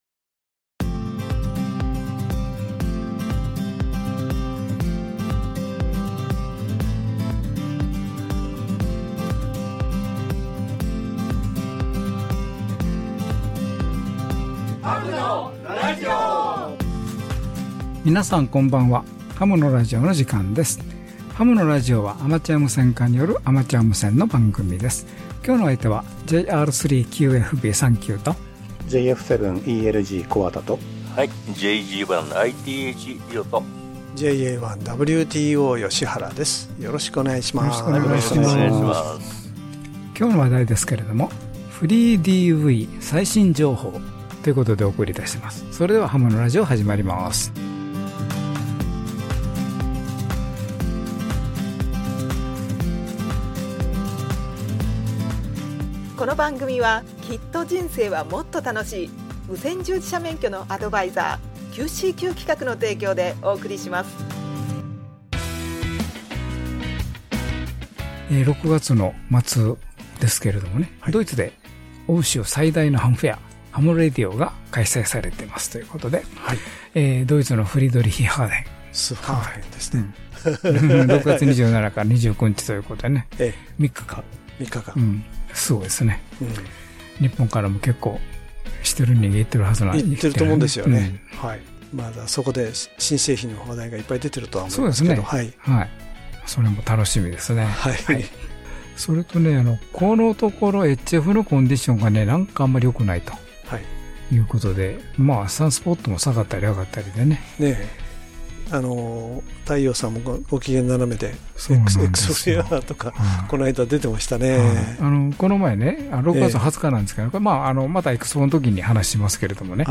たった1.5KHzの狭帯域でありながら、元の音声を忠実に再現するモードです。 音声サンプル等もお届けし、新しい「FreeDV」をご紹介します。